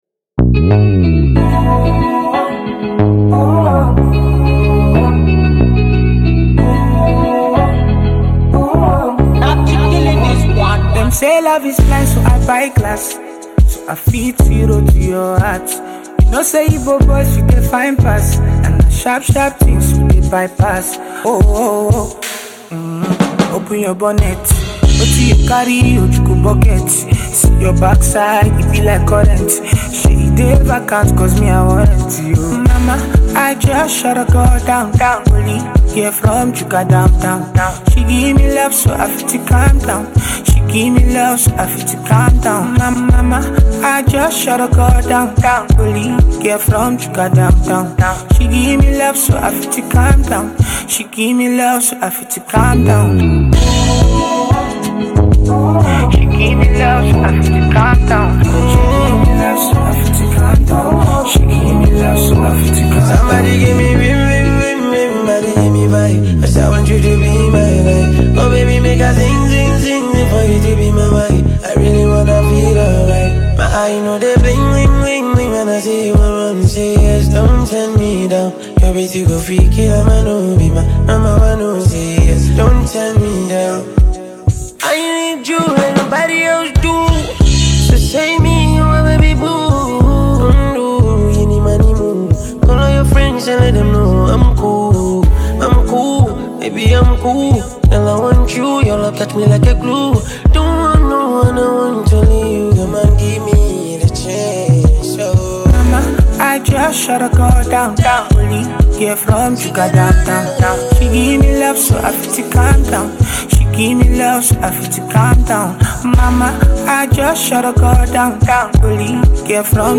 Nigerian afrobeats singer